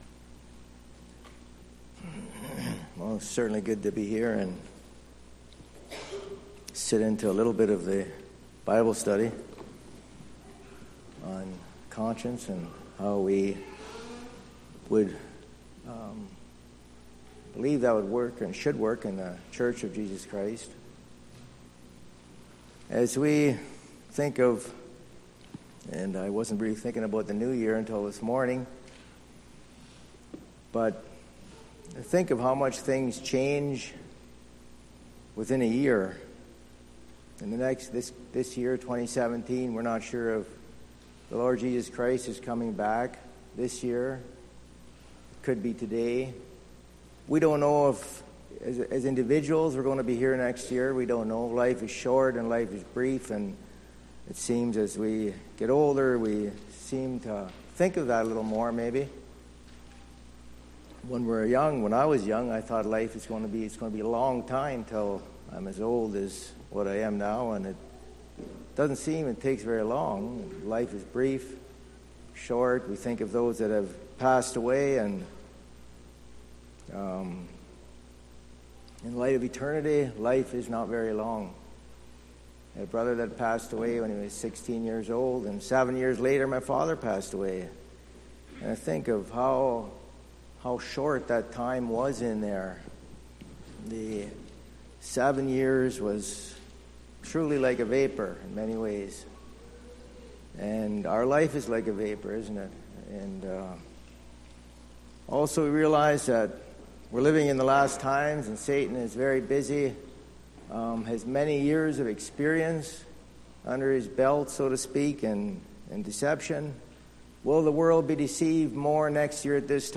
0010 Sermon.mp3